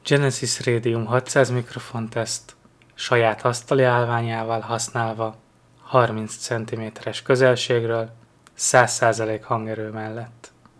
Hangminőség teszt: normál használat
Normál használat, a mikrofon előttünk van a saját állványával és a számítógép nem éppen csendes.